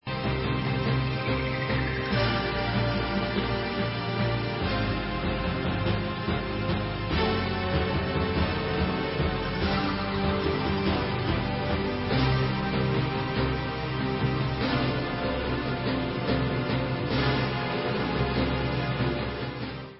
sledovat novinky v oddělení Pop/Symphonic